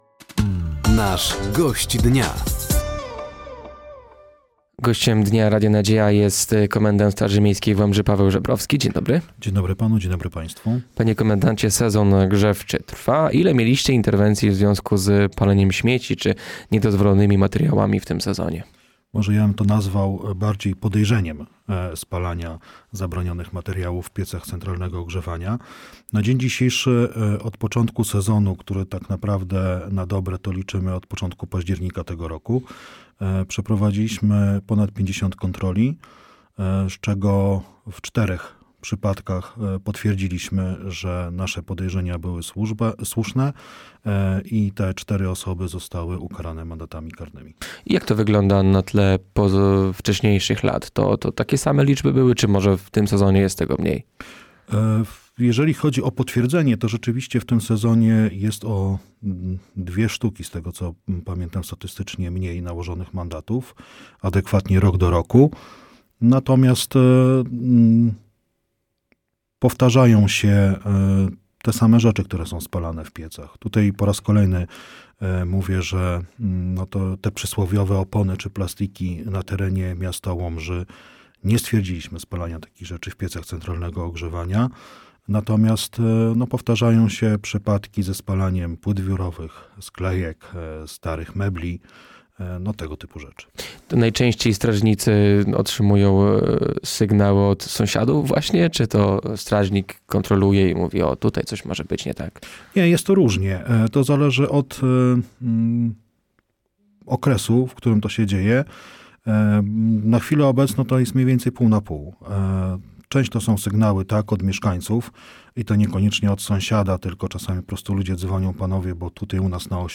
Gościem Dnia Radia Nadzieja był Paweł Żebrowski, komendant Straży Miejskiej w Łomży. Tematem rozmowy były między innymi kontrole pieców i działania strażników.